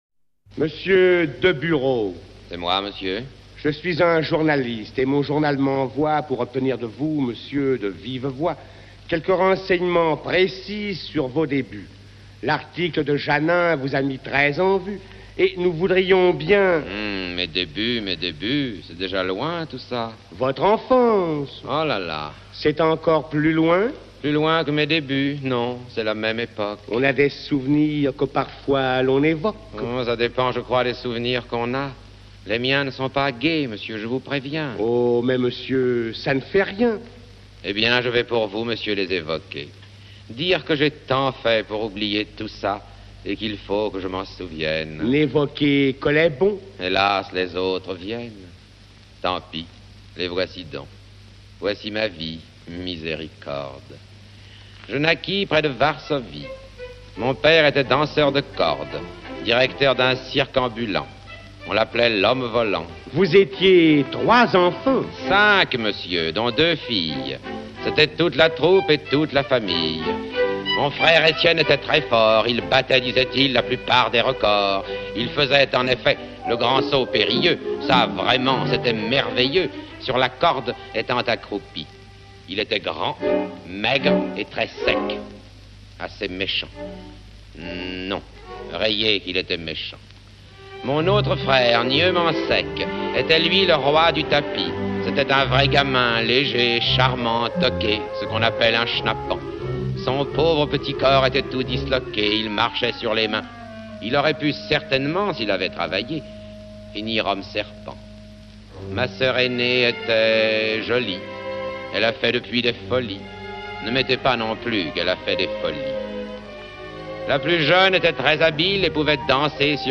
Sacha Guitry (Deburau) et Orchestre